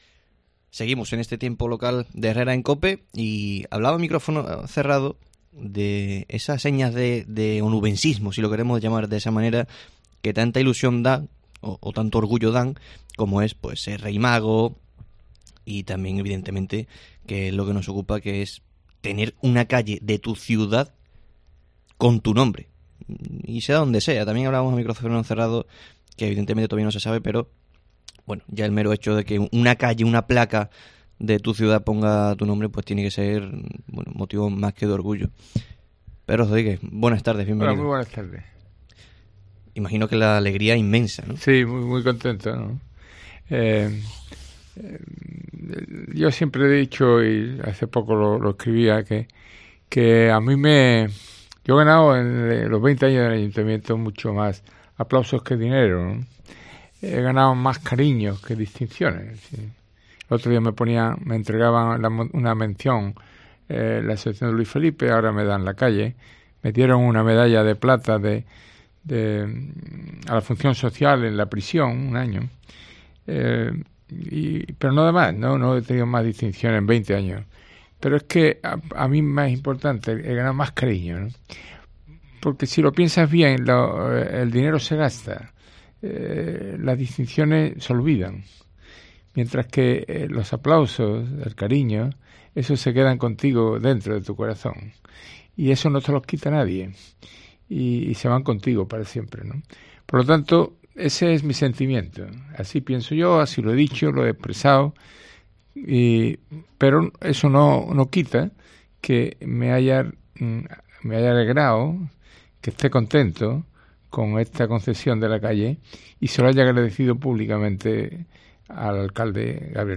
Entrevista a Pedro Rodríguez, ex alcalde de Huelva